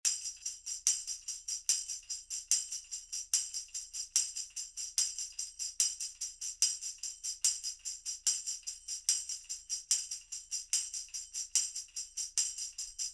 In this audio example, listen to a tambourine loop: Version A is cardioid directly in front, Version B is cardioid from behind, and Version C is omnidirectional. Note the dropoff in Volume in version B, and a bit more of the room sound in Version C.
tambourine-omnidirectional-waveinformer.mp3